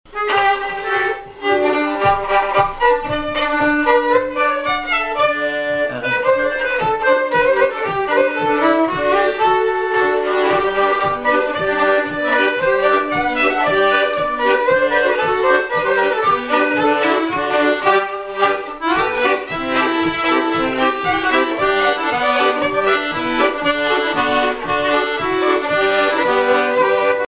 Air de danse
danse : marche
Répertoire de bal au violon et accordéon
Pièce musicale inédite